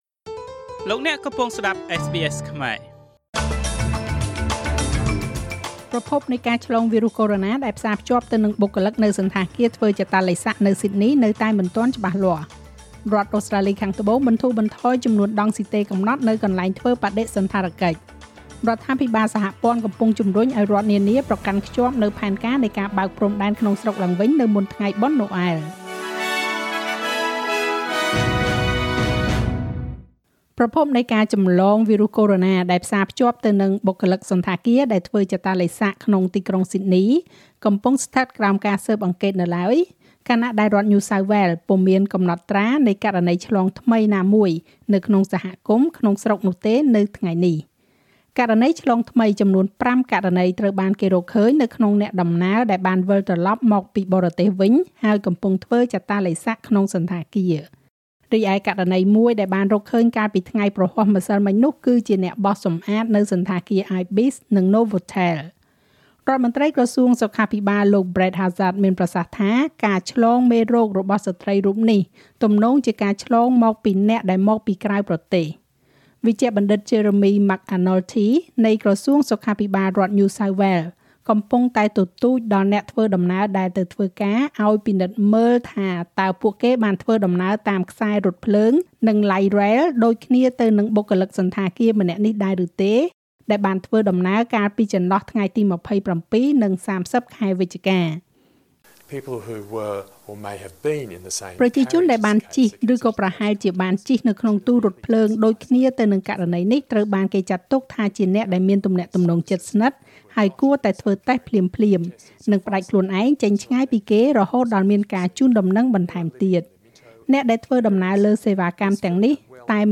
នាទីព័ត៌មានរបស់SBSខ្មែរ សម្រាប់ថ្ងៃសុក្រ ទី ៤ ខែធ្នូ ឆ្នាំ២០២០។